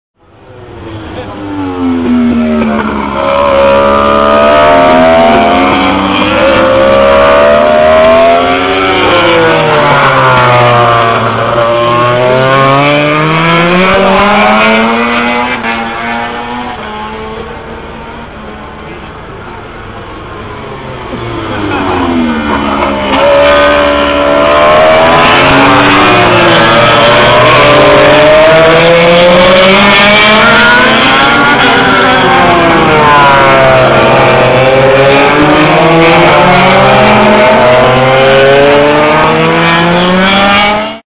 J'en profite pour faire des prises de sons afin d'enregistrer ce bruit rageur que dégagent les MotoGP.
(Lorsqu'on entend le coup de trompe, c'est qu'il s'agit soit de